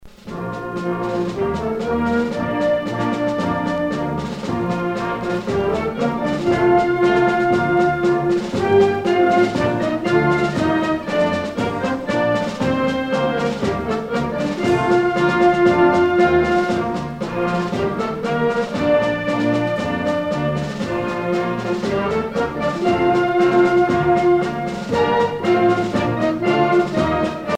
gestuel : à marcher
circonstance : militaire
Pièce musicale éditée